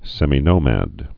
(sĕmē-nōmăd, sĕmī-)